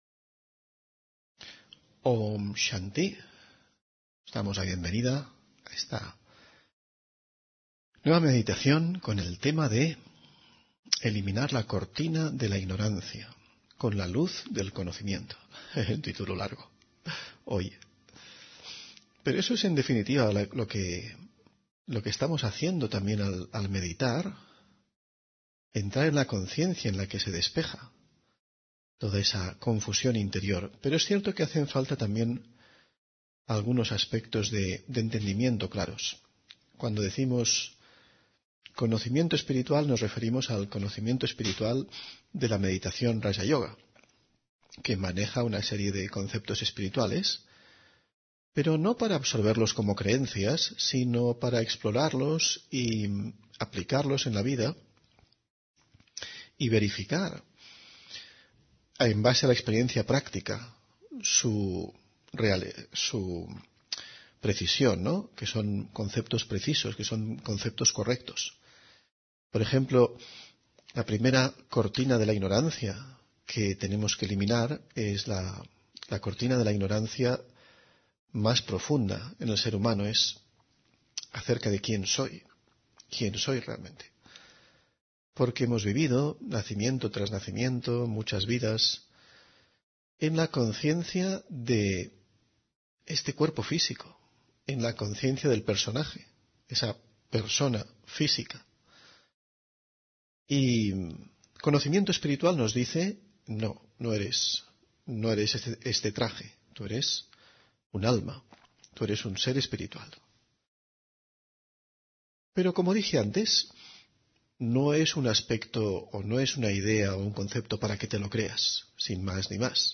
Meditación y conferencia: Eliminar la cortina de la ignorancia con la luz del conocimiento (21 Enero 2025)